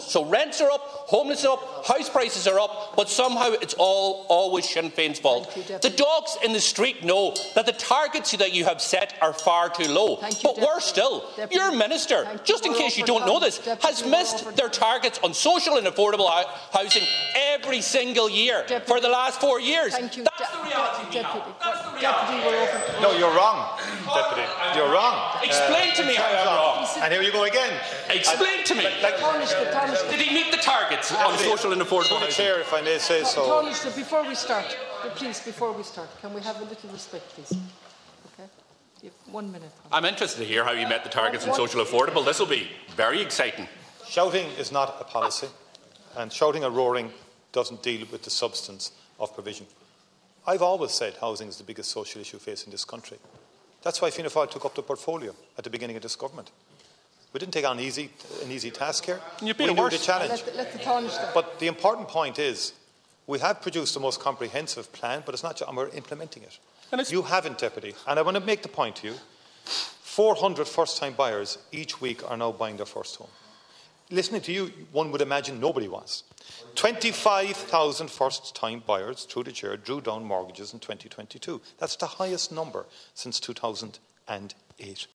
There were calls for order in the Dáil this afternoon following yet another clash between Donegal Deputy Pearse Doherty and Tánaiste Micheal Martin.
Things got heated during the oppositions questions about housing.